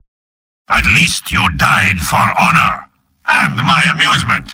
Robot-filtered lines from MvM.
Spy_mvm_dominationsoldier03.mp3